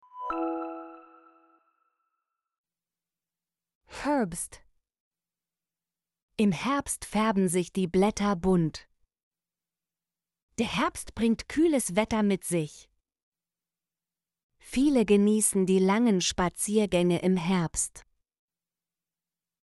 herbst - Example Sentences & Pronunciation, German Frequency List